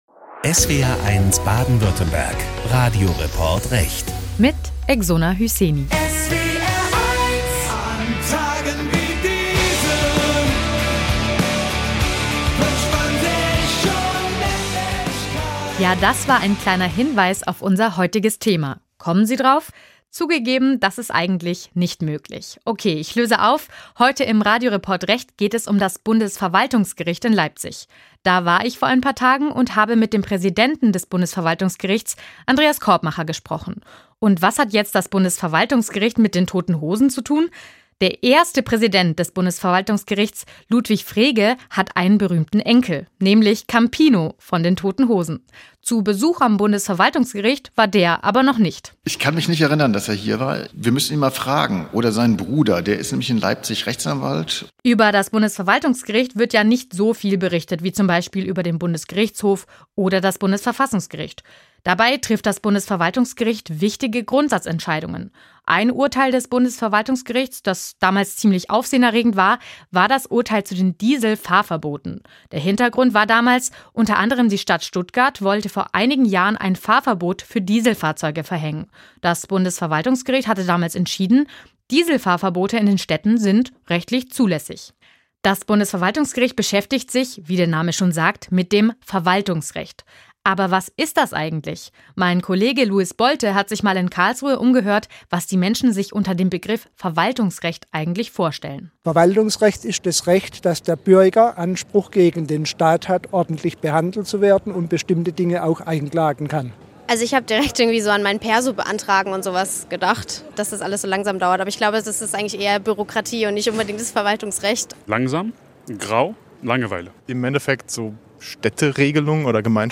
praesident-des-bundesverwaltungsgerichts-andreas-korbmacher-im-gespraech.m.mp3